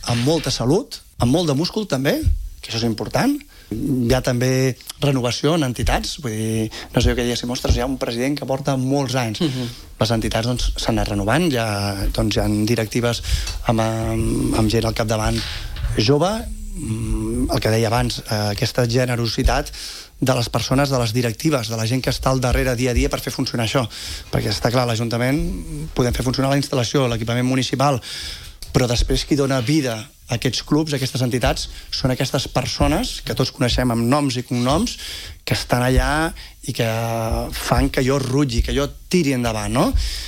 El regidor d’Esports, Manel Vicente, ha assegurat en una entrevista a Ràdio Calella Televisió que l’esport local arriba a aquesta celebració en un bon moment, amb salut i múscul.